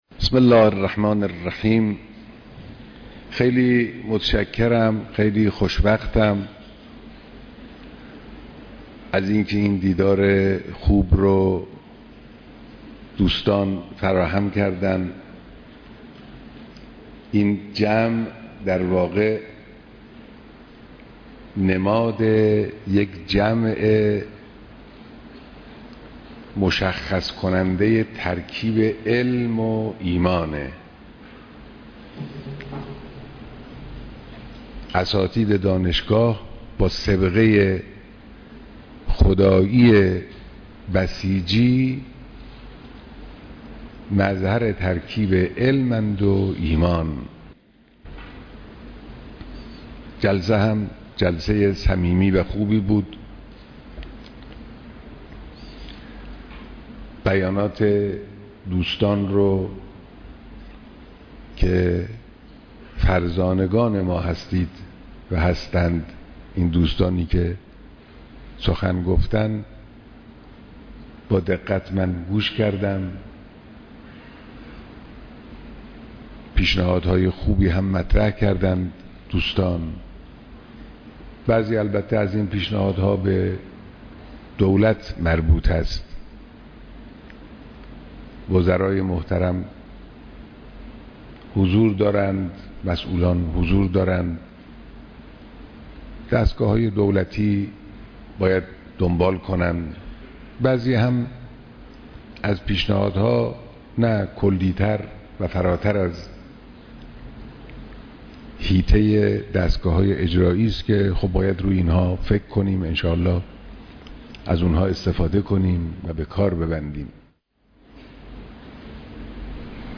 دیدار جمعی از اعضای بسیجی هیأتهای علمی دانشگاهها و مراکز آموزش عالی سراسر کشور
بيانات در ديدار اعضاى بسيجى‌ هيئت علمى دانشگاه‌ها